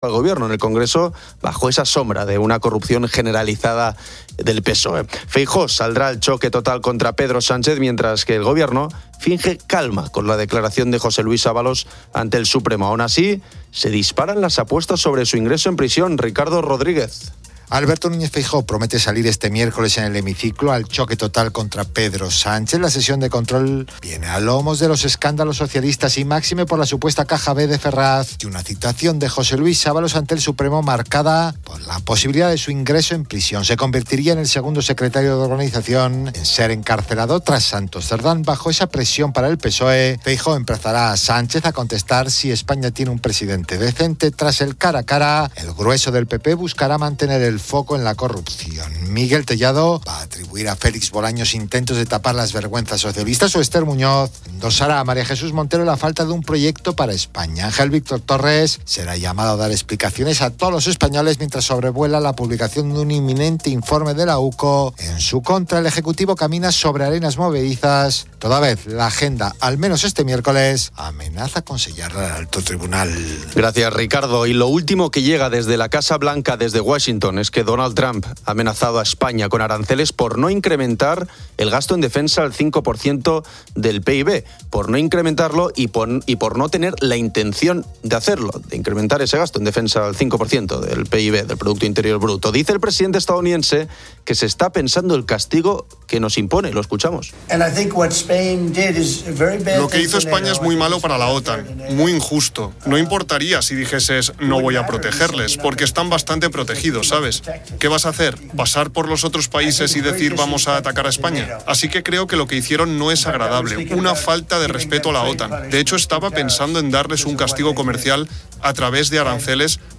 Se analizan los requisitos para ser jurado y se comparten experiencias de oyentes, incluyendo escepticismo sobre la imparcialidad y la complejidad legal.